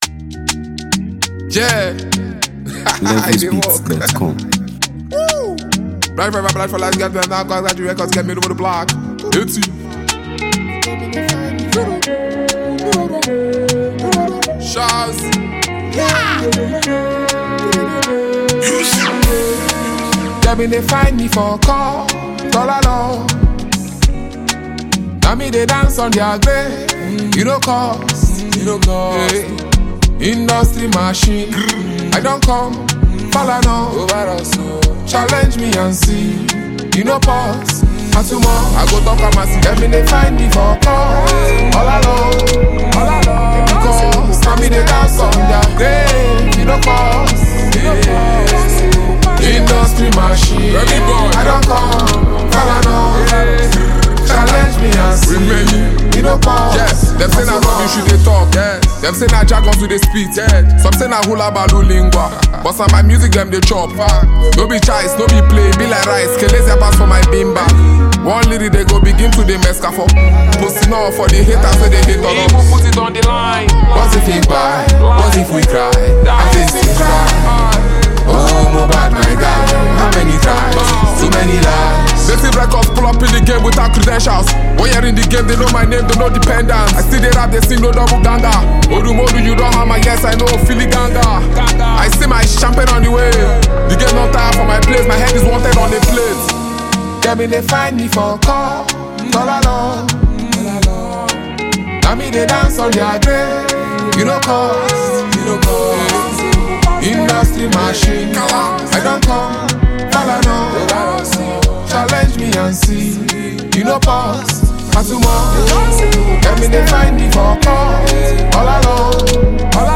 Nigeria Music 2025 3:04
the acclaimed Nigerian rap virtuoso and exceptional lyricist
With its captivating sound and confident delivery